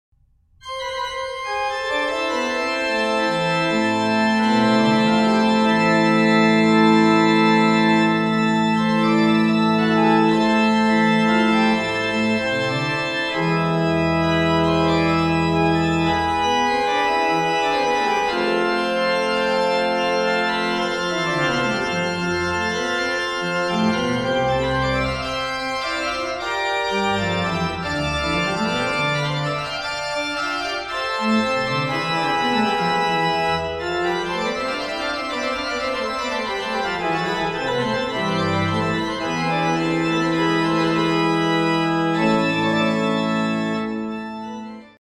orgue